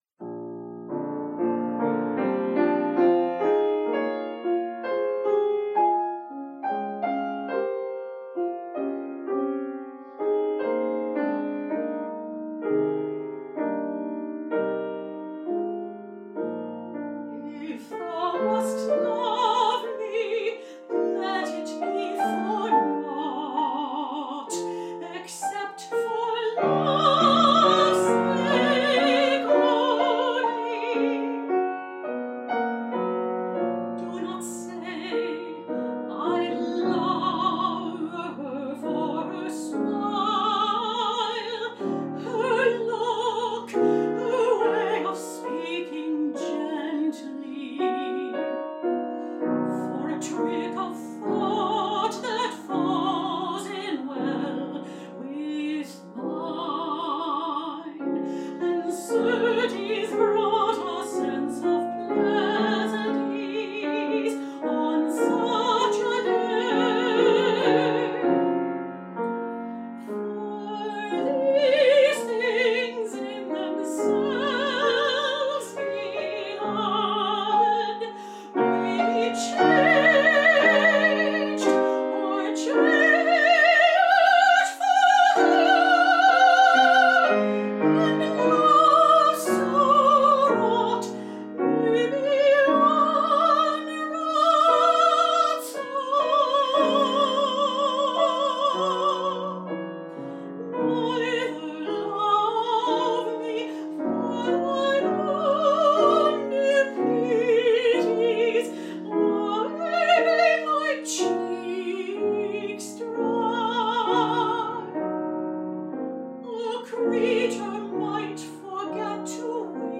for medium high voice & piano